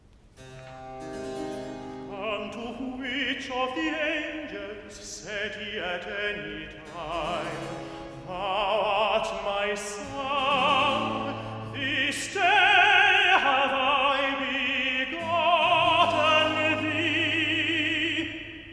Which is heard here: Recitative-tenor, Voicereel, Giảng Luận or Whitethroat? Recitative-tenor